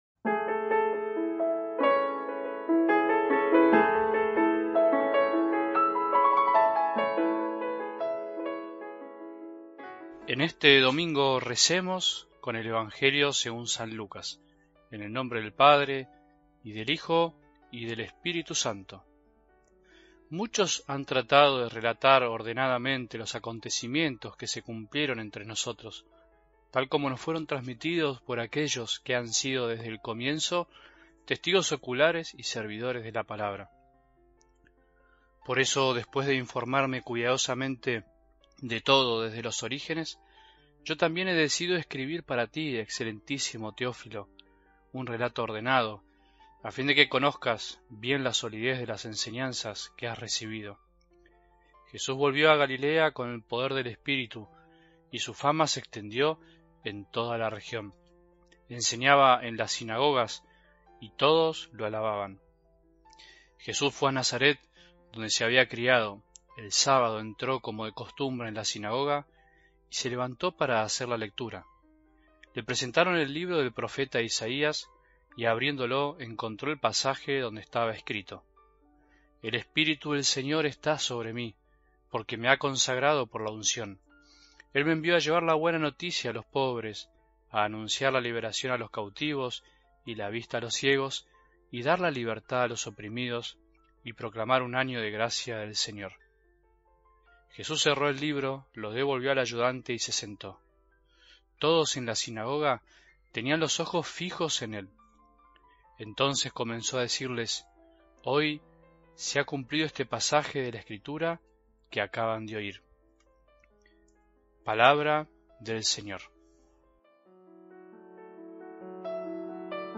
Reflexión